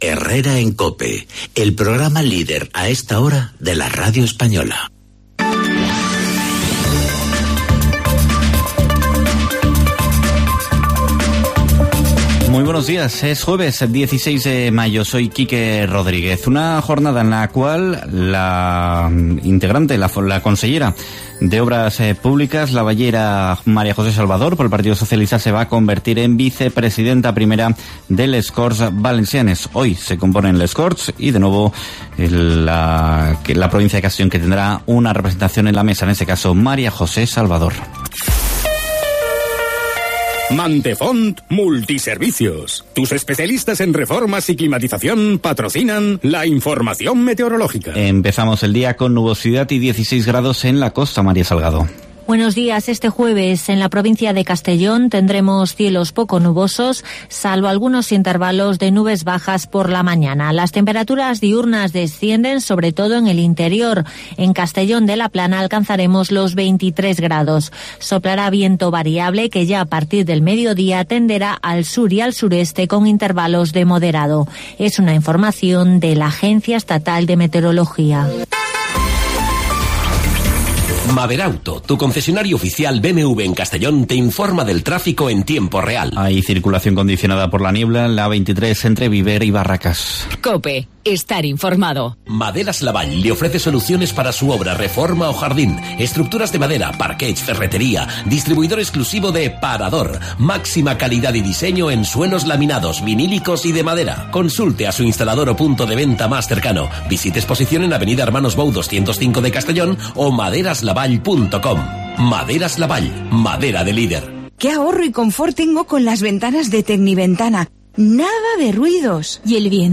Informativo 'Herrera en COPE' Castellón (16/05/2019)